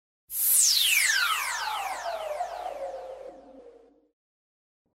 Ambient sound effects
Descargar EFECTO DE SONIDO DE AMBIENTE NATURALEZA244 - Tono móvil
naturaleza244_.mp3